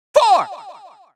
countIn4Farthest.wav